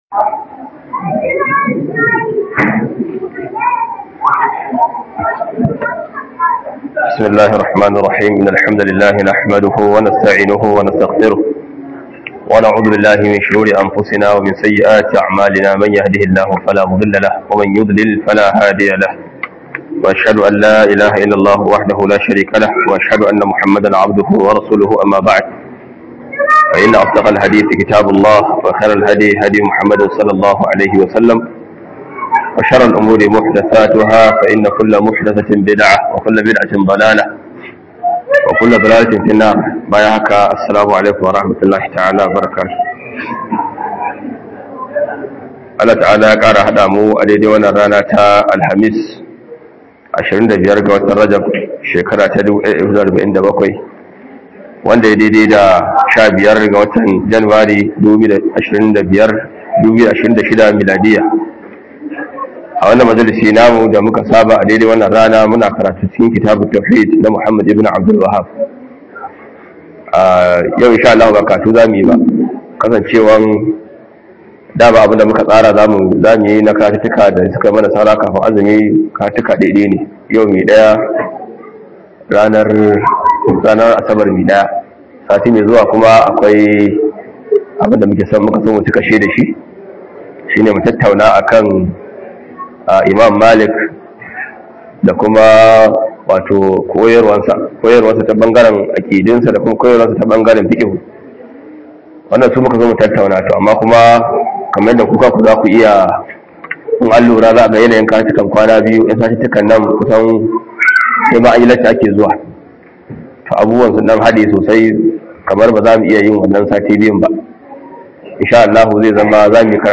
001 Imam Malik da Malikawan Gaskiya a Najeriya - Jerangiyar Laccoci